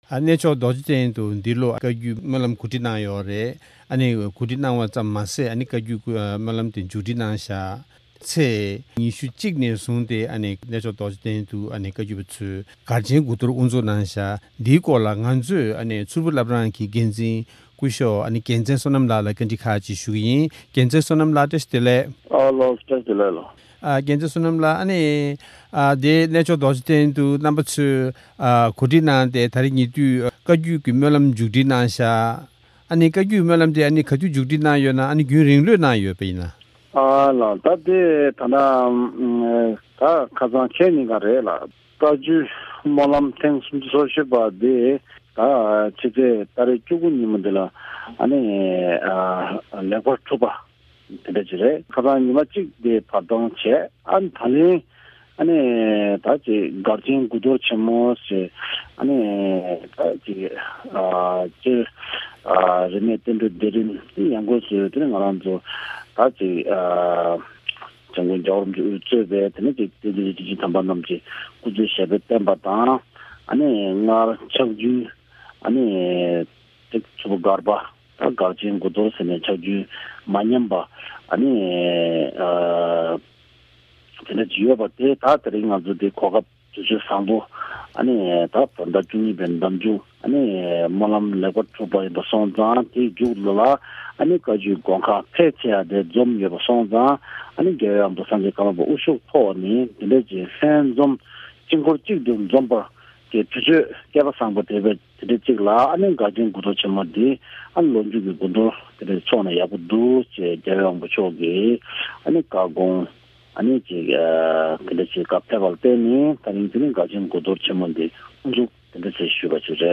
གནས་འདྲི་ཞུས་ཏེ་ཕྱོགས་བསྒྲིགས་གནང་བའི་གནས་ཚུལ